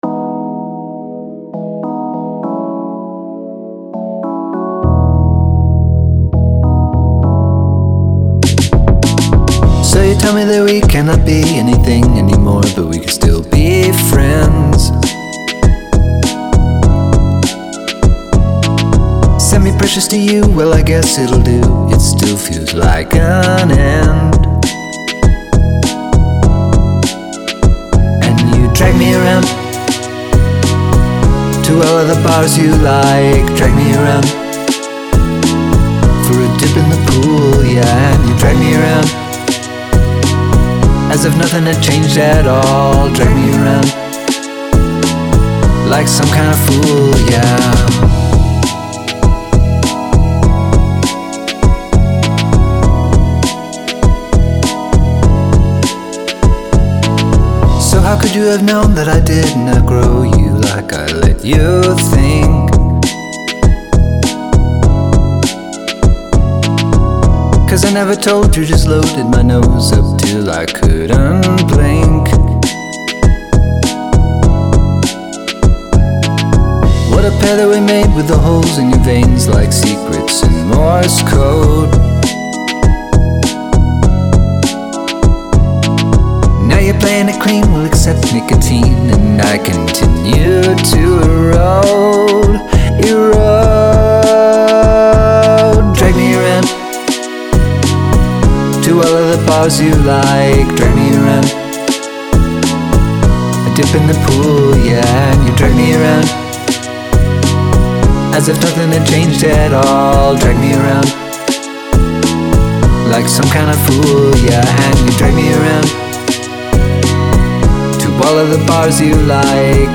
I really like the synth lead under the choruses.